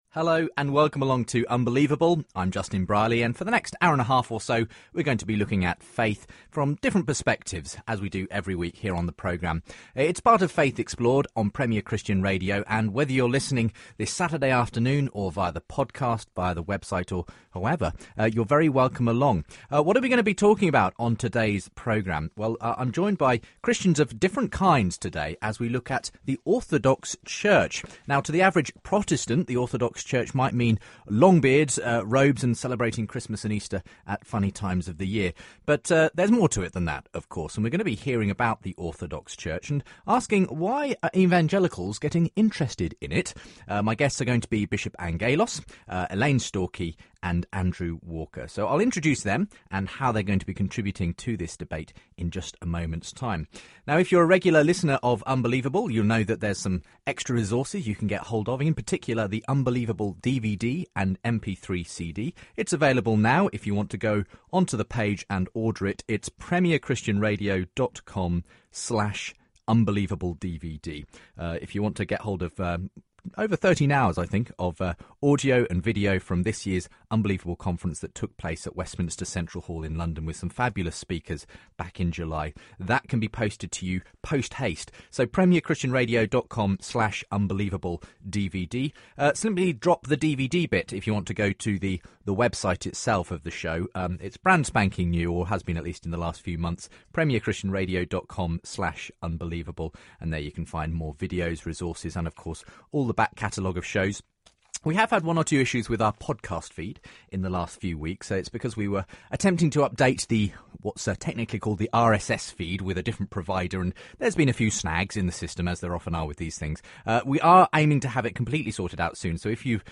In this discussion we find out about the history of the Orthodox Church and some of its distinctive features.